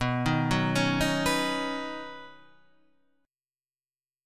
Badd9 Chord